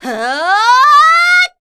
assassin_w_voc_attack04_c.ogg